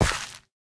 Minecraft Version Minecraft Version snapshot Latest Release | Latest Snapshot snapshot / assets / minecraft / sounds / block / rooted_dirt / step4.ogg Compare With Compare With Latest Release | Latest Snapshot
step4.ogg